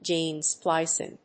アクセントgéne splícing